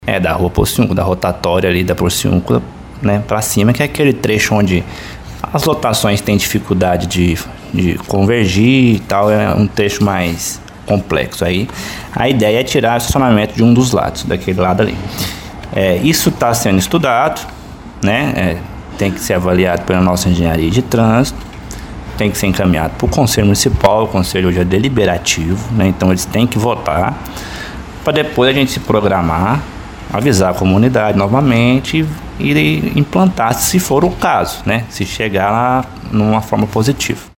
O secretário Marcos Vinícius de Oliveira Santos deu mais detalhes: